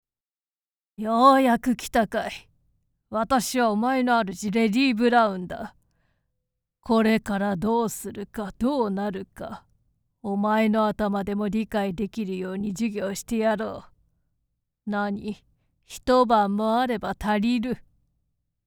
厳しめな口調と深い眉間のしわで、あまり評判はよくない。
SampleVoice01